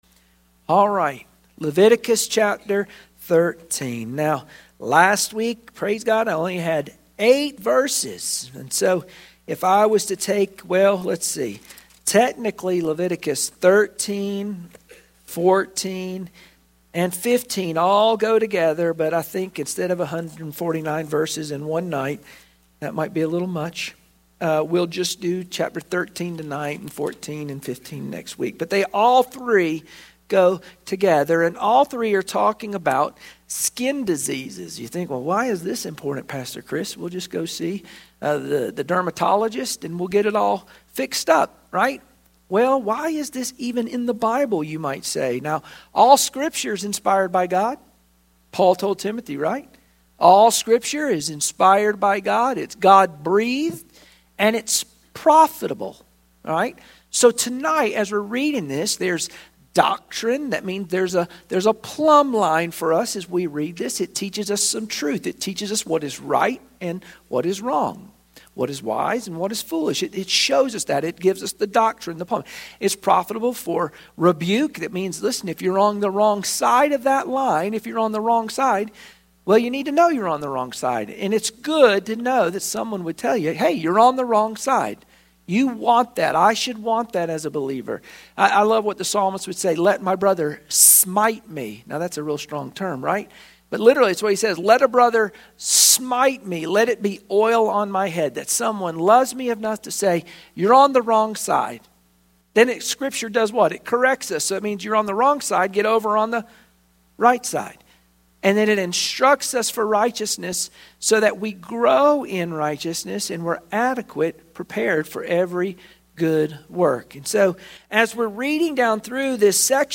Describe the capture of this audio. Wednesday Prayer Mtg Passage: Leviticus 13 Service Type: Wednesday Prayer Meeting Share this